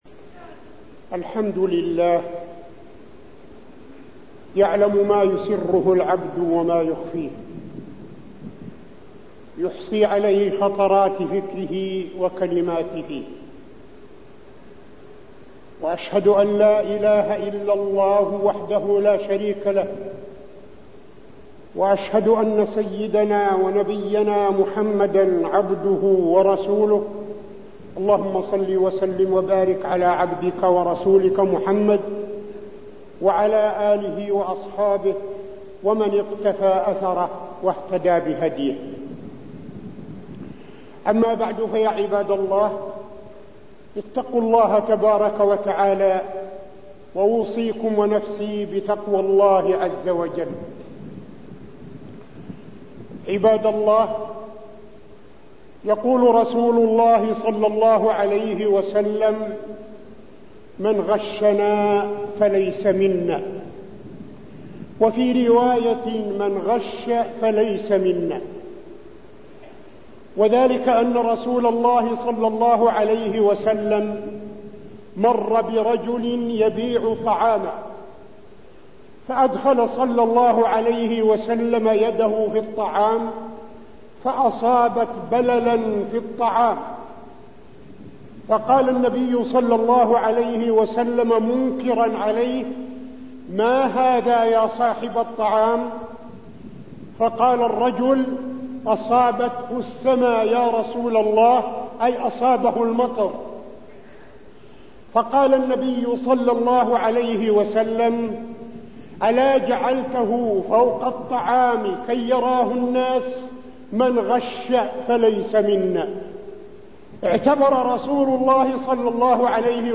تاريخ النشر ١٣ محرم ١٤٠٦ هـ المكان: المسجد النبوي الشيخ: فضيلة الشيخ عبدالعزيز بن صالح فضيلة الشيخ عبدالعزيز بن صالح من غشنا فليس منا